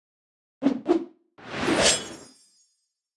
Sfx_Anim_Base_Battle_Healer.wav